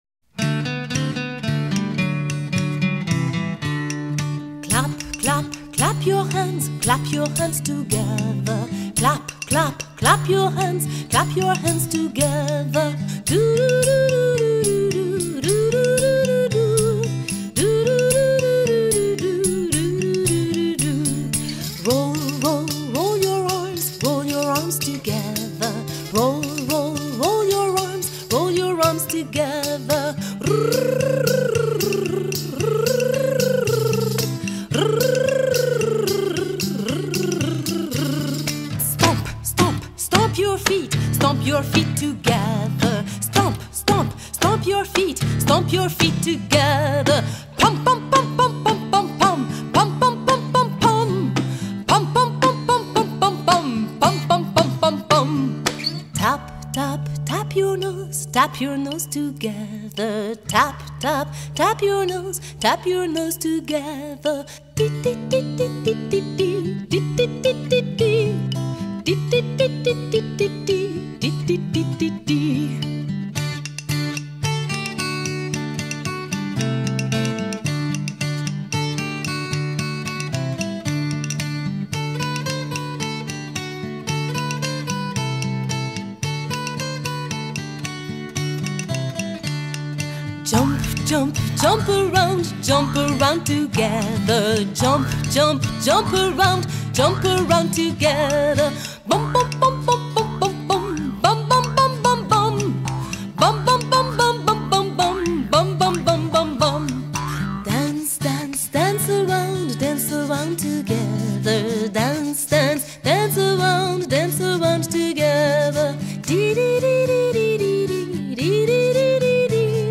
Chant à gestes en anglais pour chanter et bouger avec la musique.